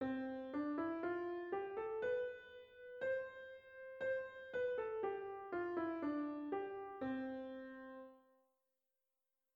A continuación tienes un ejemplo de este grupo de figuras dentro de una melodía sencilla:
Ejemplo de corchea y dos semicorcheas en una melodía